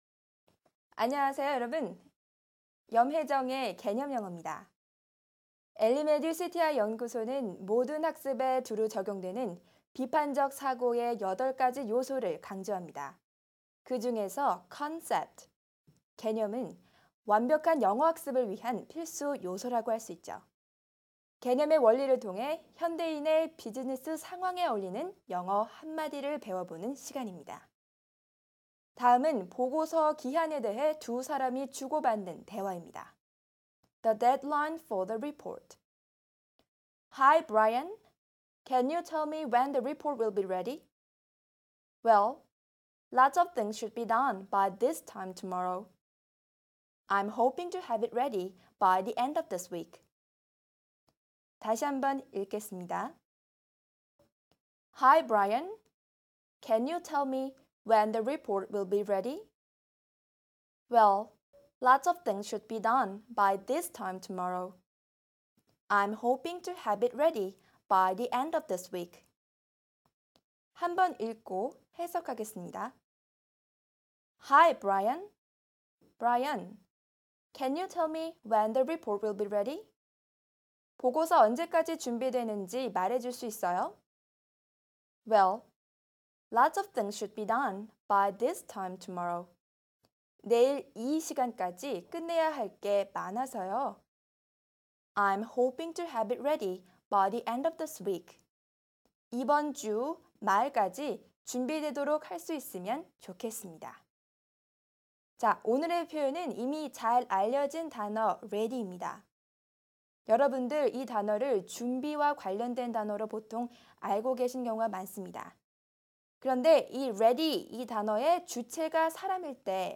다음은 보고서 기한(The deadline for the report)에 대해 두 사람이 주고받는 대화입니다.